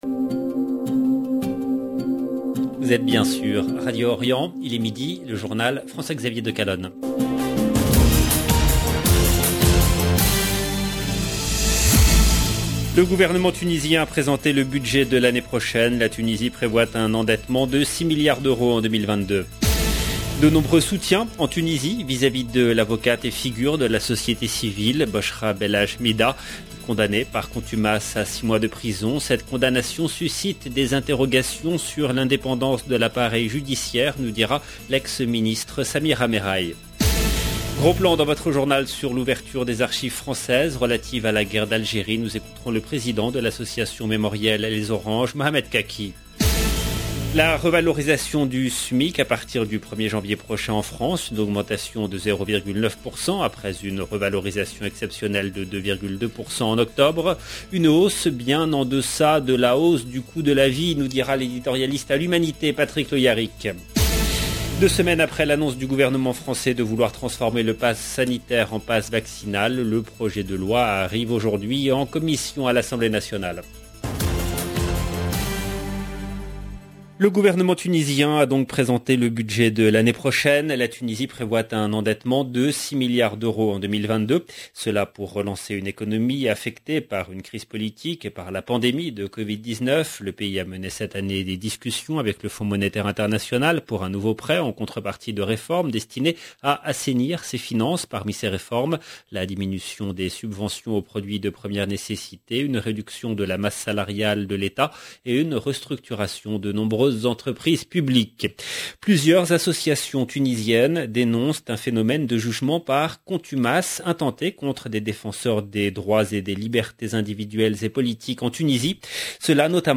LE JOURNAL DE MIDI EN LANGUE FRANCAISE DU 29/12/21